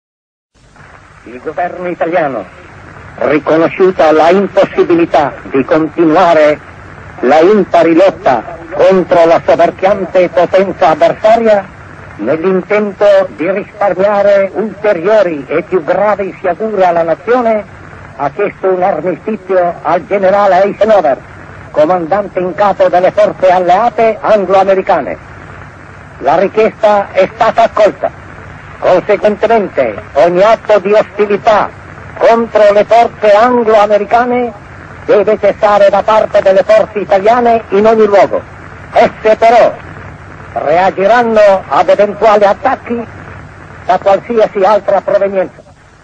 Pietro-Badoglio-Annuncia-Al-Mondo-La-Capitolazione-DellItalia-del-8-settembre-1943.mp3